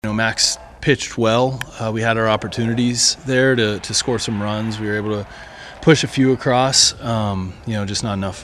Kelly says the Blue Jays’ Max Scherzer, a three-time Cy Young Award winner, was typically tough to handle.